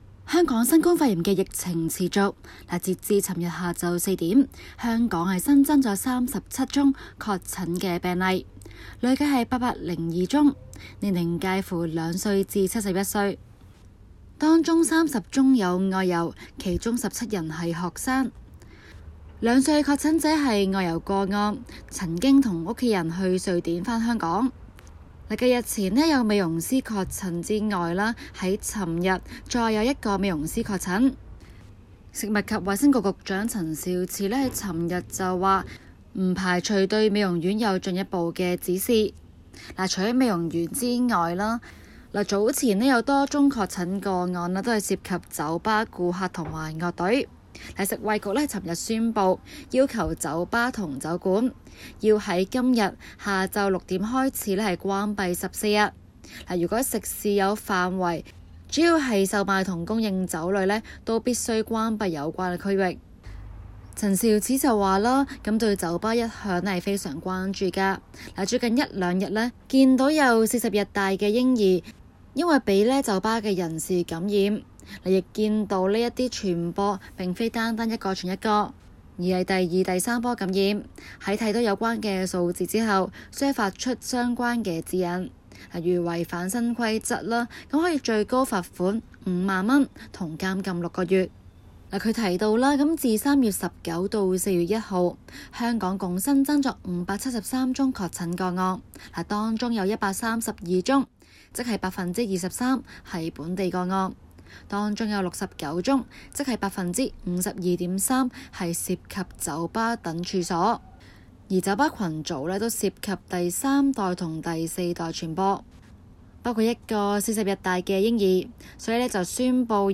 今期【中港快訊】環節報導香港酒吧業被確定為傳播新冠肺炎重災區，港府勒令關閉14日。